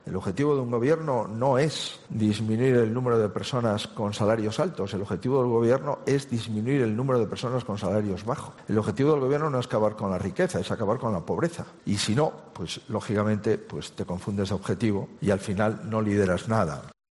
"No conozco ningún país que le vaya bien si a sus empresas no les va bien", ha dicho Feijóo en la inauguración del foro anual Talent, que reúne en Madrid a líderes empresariales, políticos y sociales para impulsar proyectos de talento.